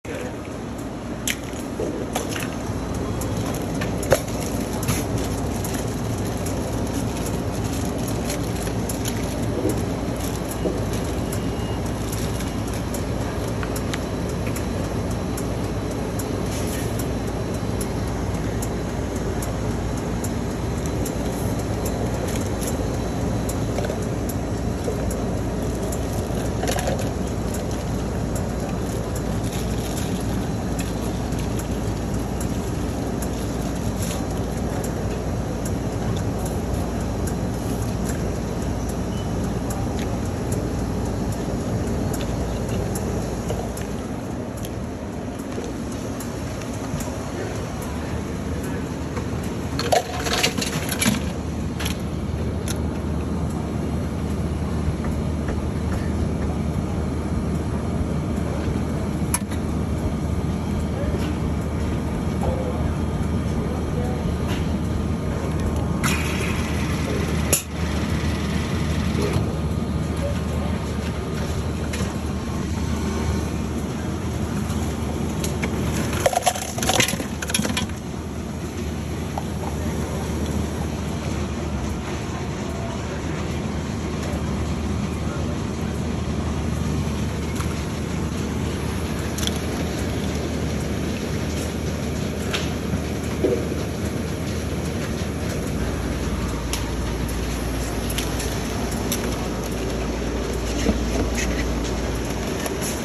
Location: AMC Movie Theater Roosevelt Field Mall
• churning of the slushy machine
• crinkling as plastic wrapping is torn off
• clanking of ice as it falls into a cup
• spurting of liquid from the soda machine
• a distant sound of popping as the popcorn is refilled in it’s case
Movie-Theater-Field-Reccording.MOV.mp3